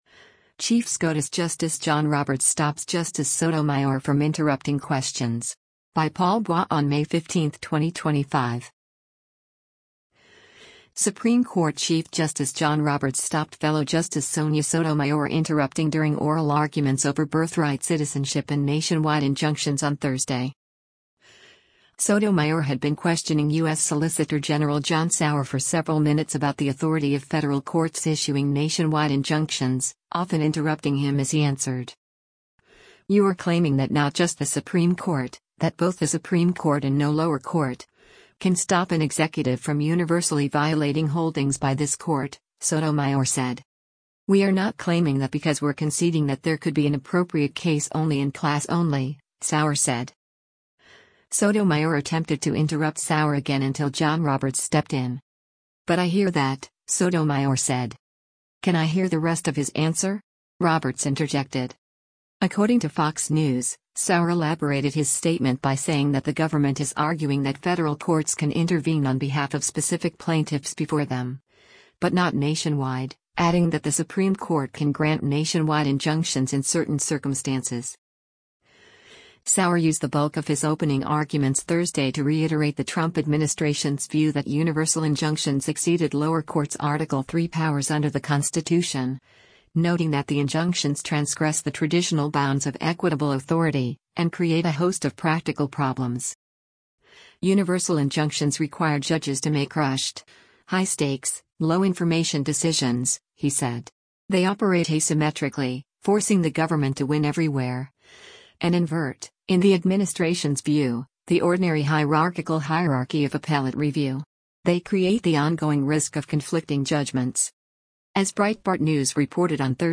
Supreme Court Chief Justice John Roberts stopped fellow Justice Sonia Sotomayor interrupting during oral arguments over birthright citizenship and nationwide injunctions on Thursday.
Sotomayor had been questioning U.S. Solicitor General John Sauer for several minutes about the authority of federal courts issuing nationwide injunctions, often interrupting him as he answered.
Sotomayor attempted to interrupt Sauer again until John Roberts stepped in.